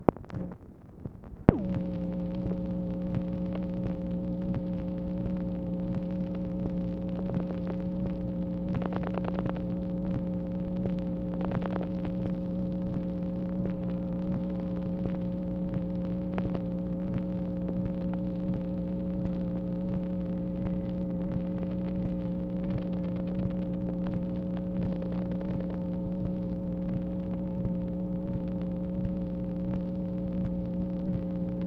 MACHINE NOISE, September 4, 1964